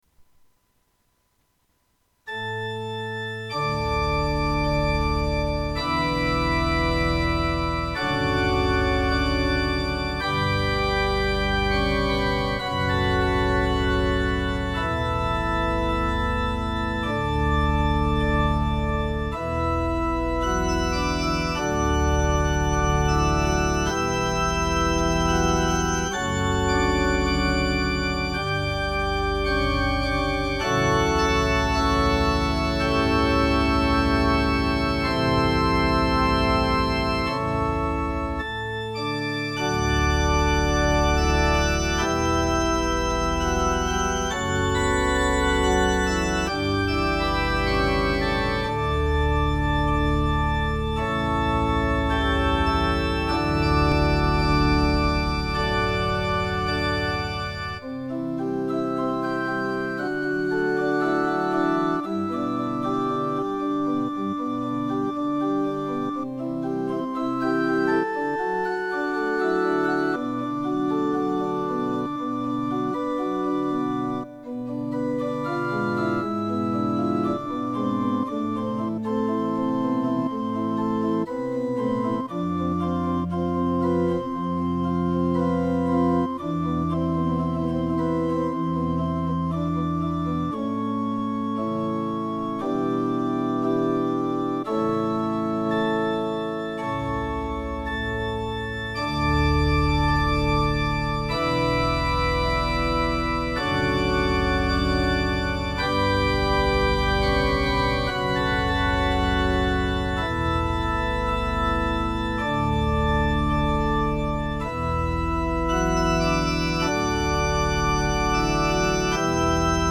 Pro tento týden jsem si pro vás připravil opět několik klavírních doprovodů písniček, které si můžete zazpívat třeba i se sourozenci nebo rodiči.